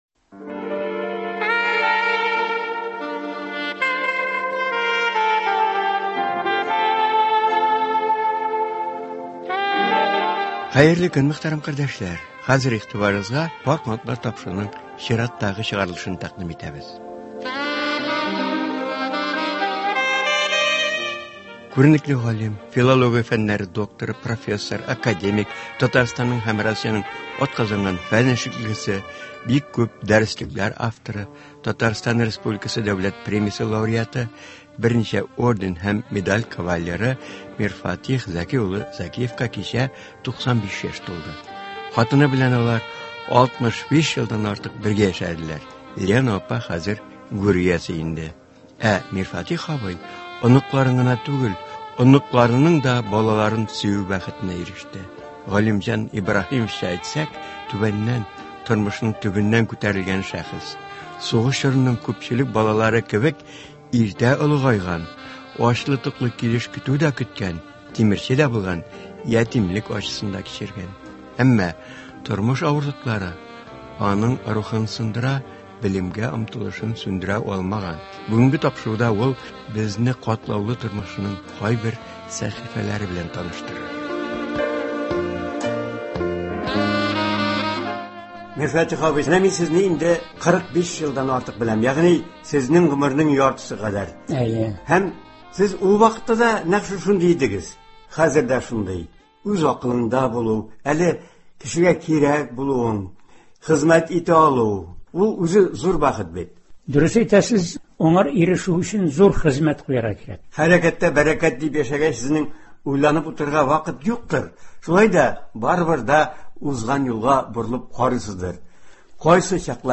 Алар белән әңгәмә дә матур гаилә кору, җәмгыятькә файдалы балалар үстерү турында бара.